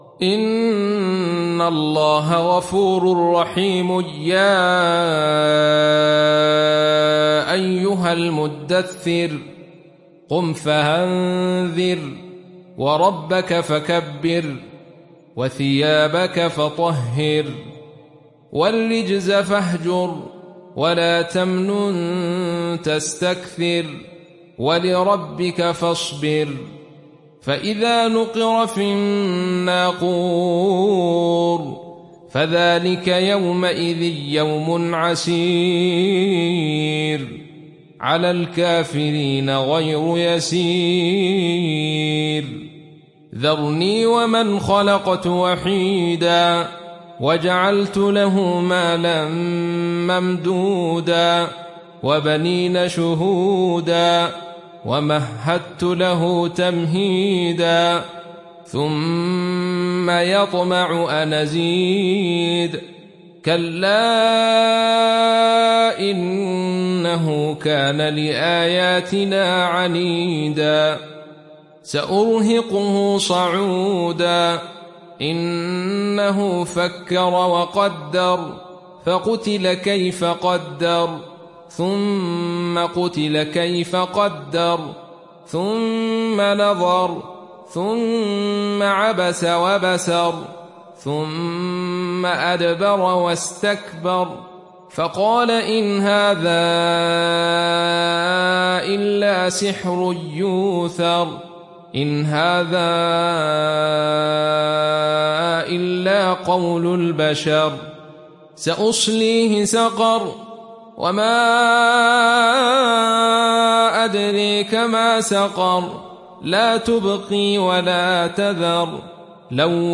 সূরা আল-মুদ্দাস্‌সির mp3 ডাউনলোড Abdul Rashid Sufi (উপন্যাস Khalaf)